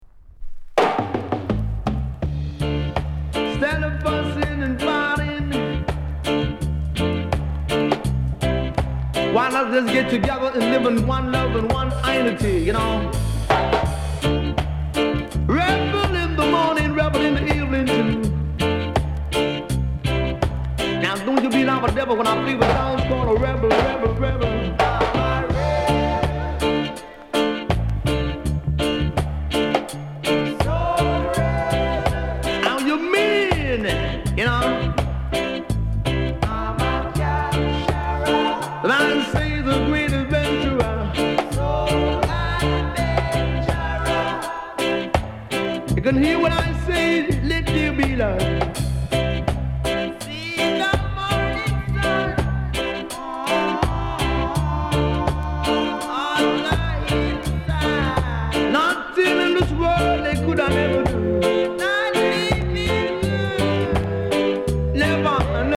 DEE JAY CUT